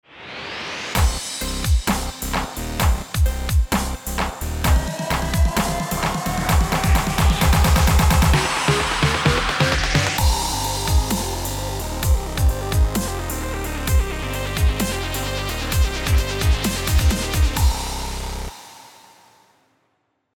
Electronic
ActionDriving